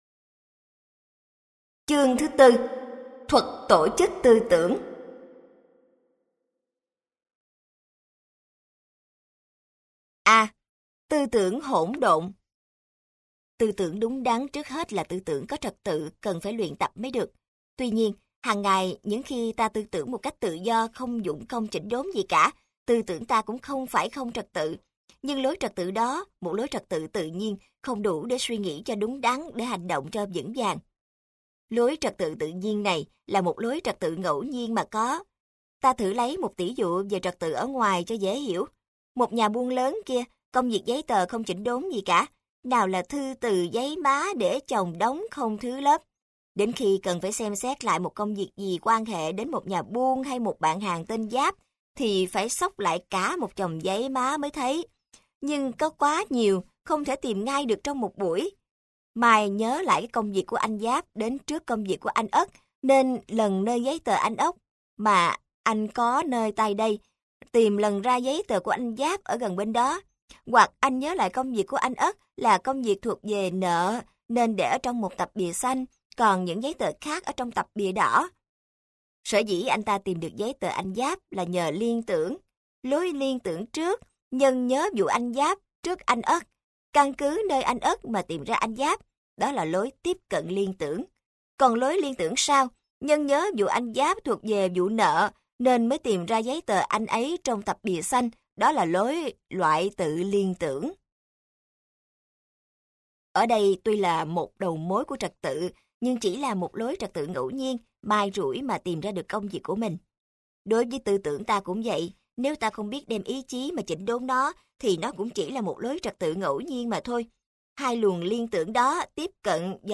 Sách nói Óc Sáng Suốt - Hạt Giống Tâm Hồn - Sách Nói Online Hay